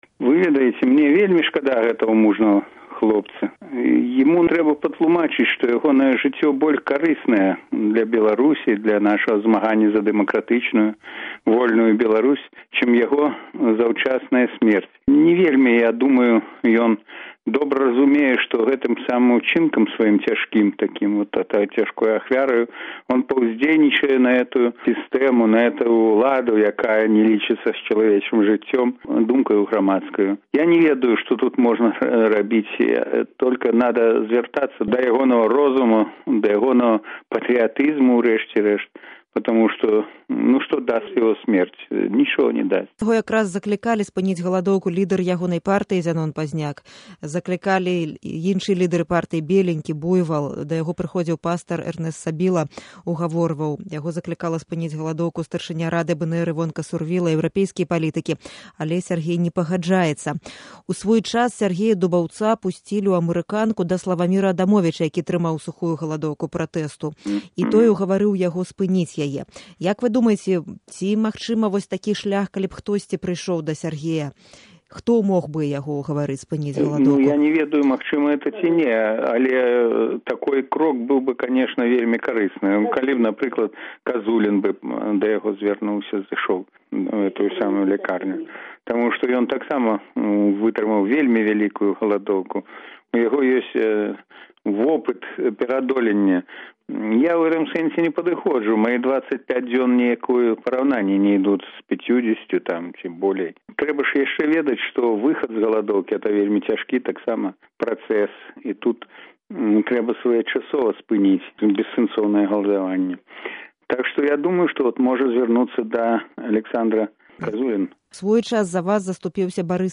Інтэрвію